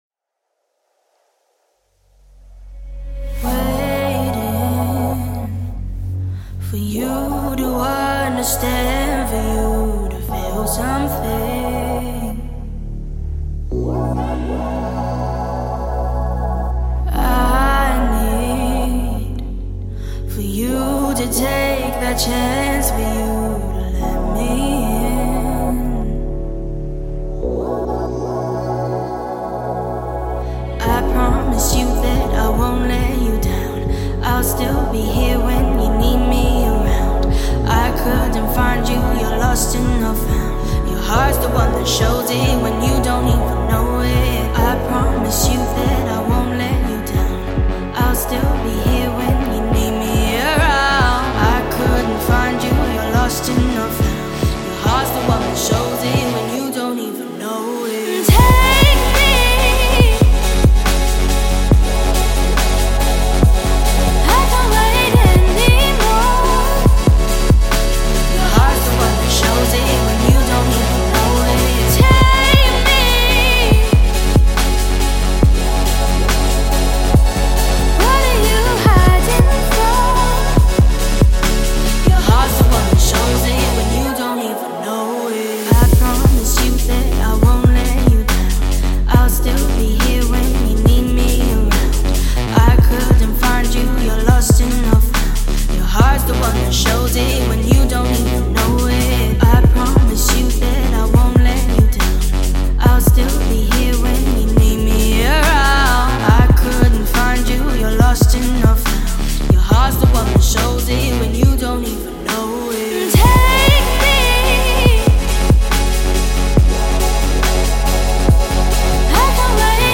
# Pop # Future # Dance # Electropop # Electronic Pop